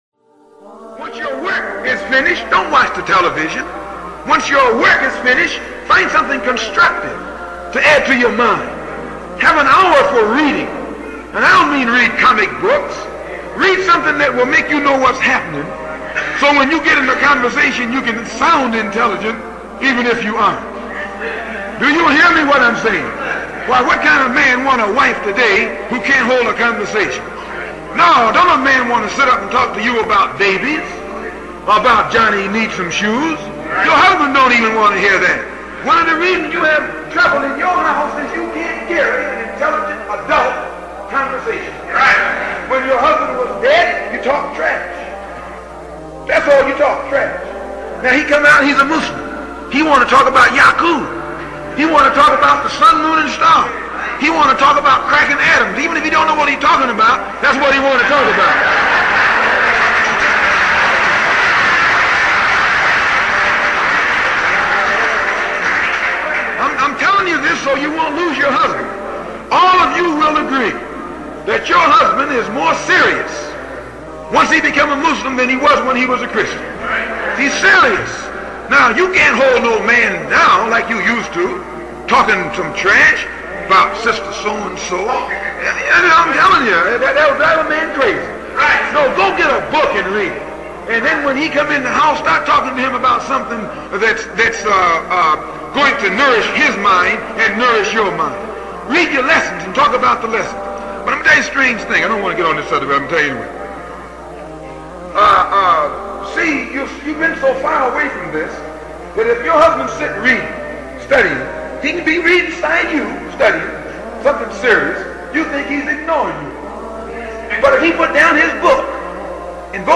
A short segment from one of Malcolm X's speeches in the early 1960s.